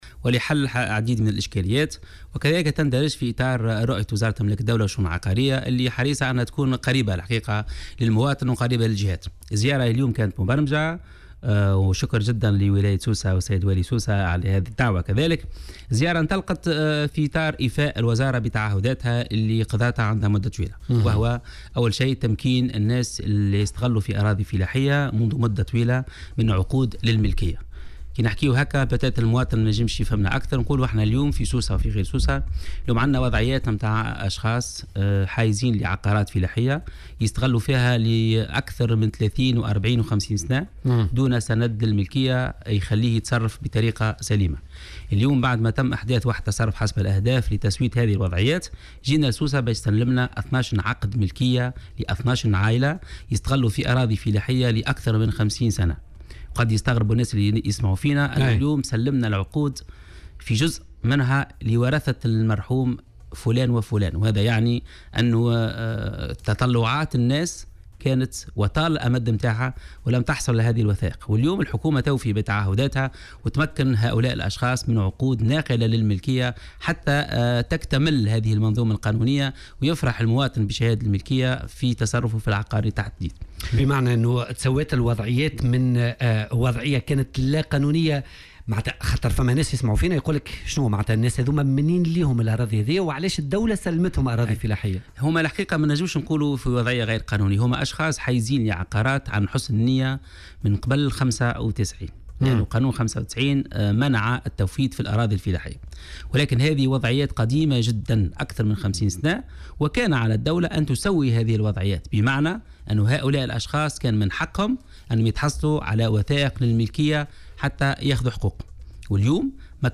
قال وزير أملاك الدولة والشؤون العقارية، هادي الماكني، في حوار مسجّل مع "بوليتيكا" تم بثّه اليوم الاثنين على "الجوهرة اف أم" أنه تم تسليم 12 عقد ملكية لعقارات تم التحوّز عليها في سوسة في اطار تسوية وضعيات العقارات ذات صبغة فلاحية.